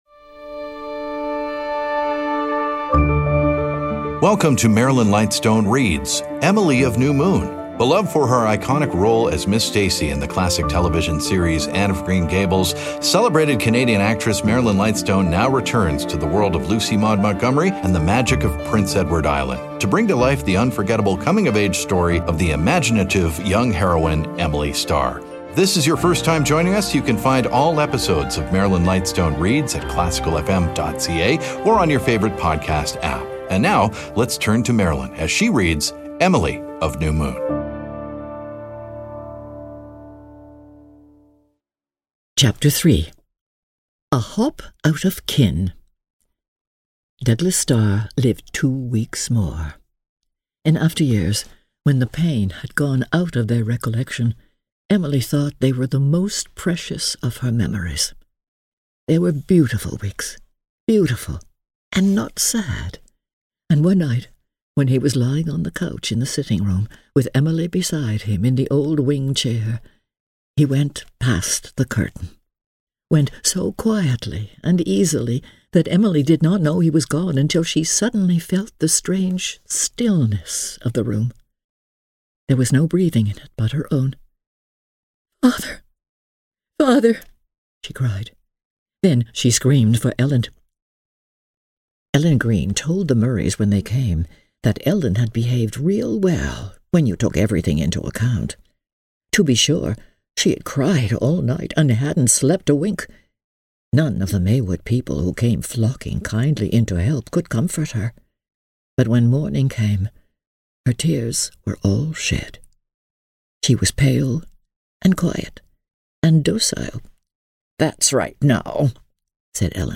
Emily Of New Moon: Chapters 3-4 Marilyn Lightstone Reads podcast
Theater Arts Marilyn Lightstone Zoomer Podcast Network Society Audio Drama Vanity Fair Content provided by Marilyn Lightstone and Zoomer Podcast Network.